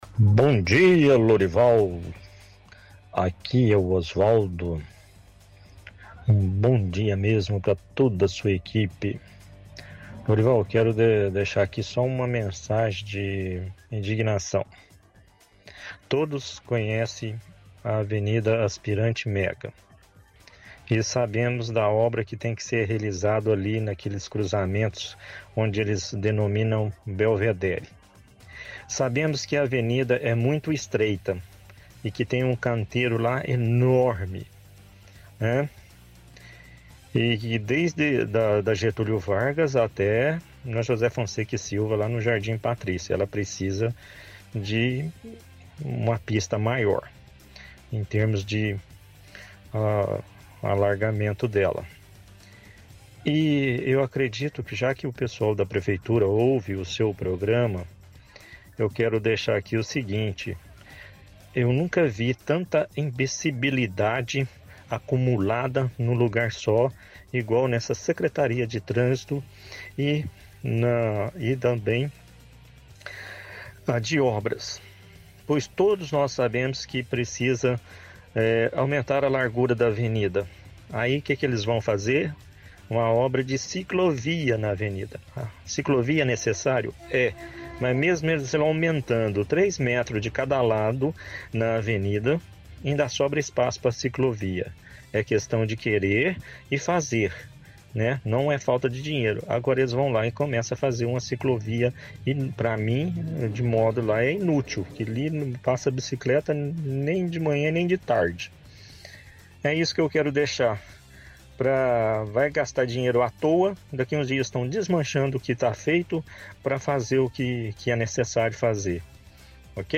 – Ouvinte reclama do cruzamento do Belvedere e fala que nunca viu tanta imbecilidade como na secretaria de obras.